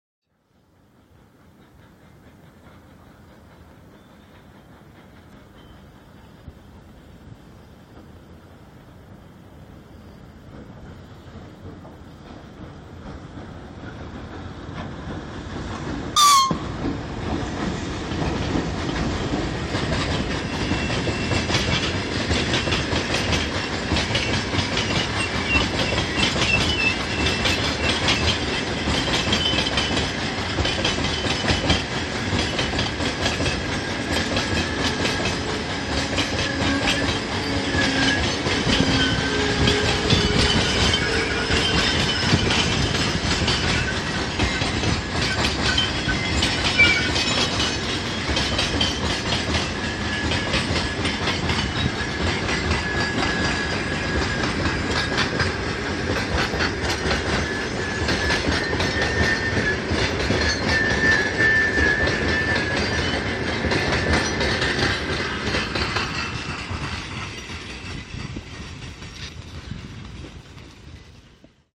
描述：一辆货运列车经过，一辆长途快车紧随其后。
这是一个纪念性的录音。MKH20和MKH30，Korg MR1.
标签： 货物 道口 距离 表达 现场记录 货运 传球 铁路 火车 运输
声道立体声